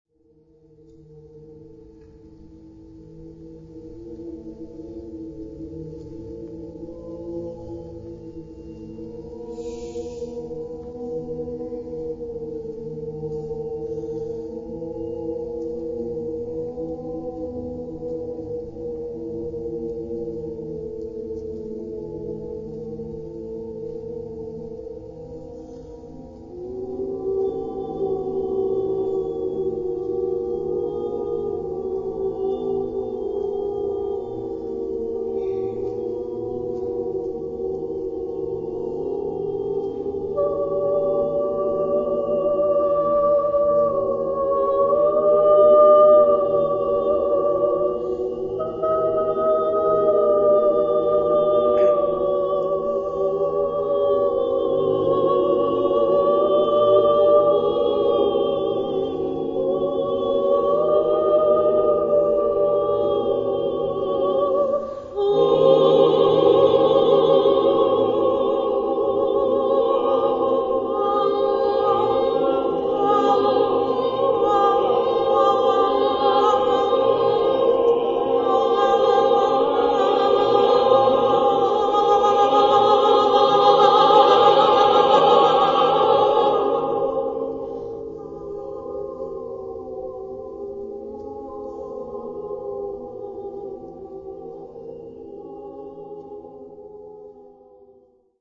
SATB (4 voix mixtes) ; Partition complète.
Profane Type de choeur : SATB (4 voix mixtes )
Tonalité : atonal